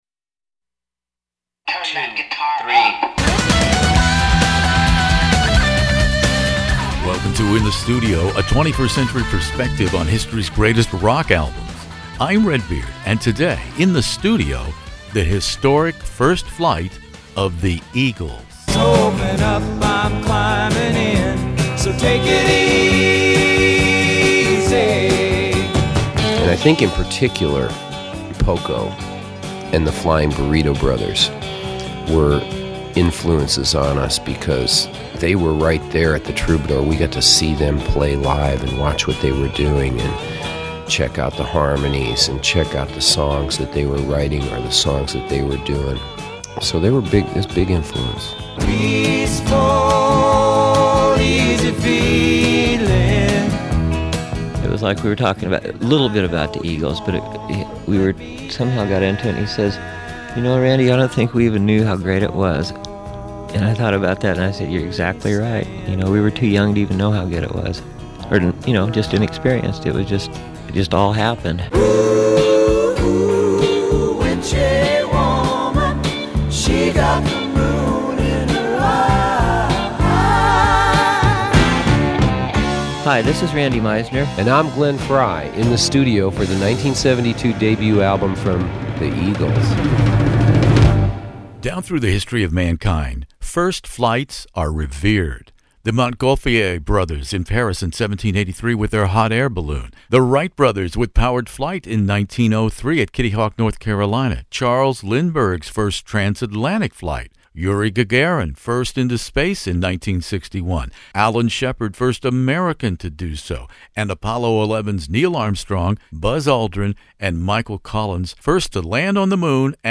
One of the world's largest classic rock interview archives, from ACDC to ZZ Top